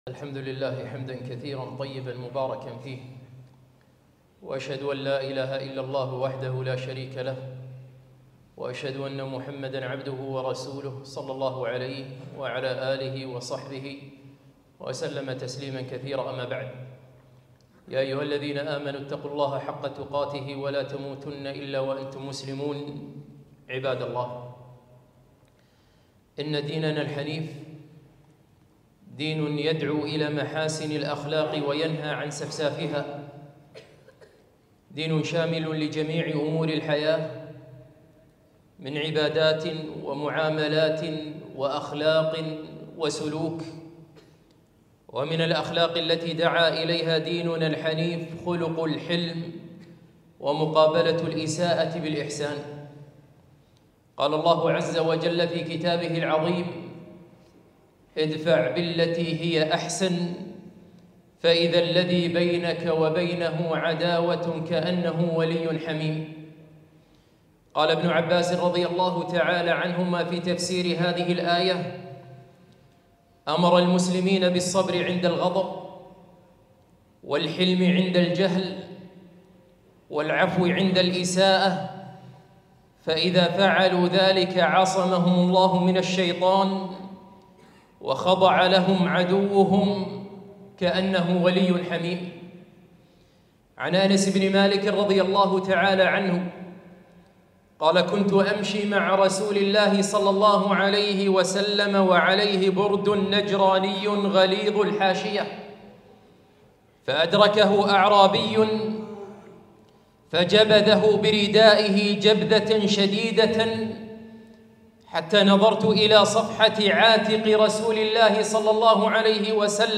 خطبة - الحلم ومقابلة الإساءة بالإحسان